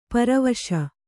♪ paravaśa